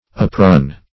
Search Result for " uprun" : The Collaborative International Dictionary of English v.0.48: Uprun \Up*run"\, v. i. To run up; to ascend.